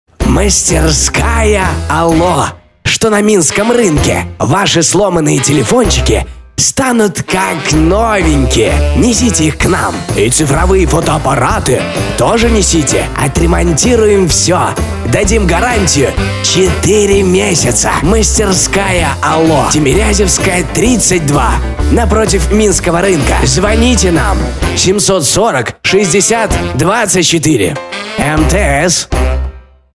Ролик с пародией на старца